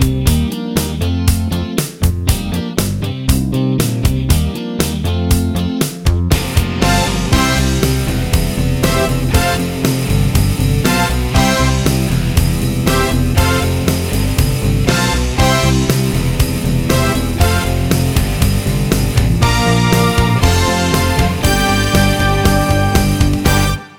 Two Semitones Down Pop (1980s) 3:45 Buy £1.50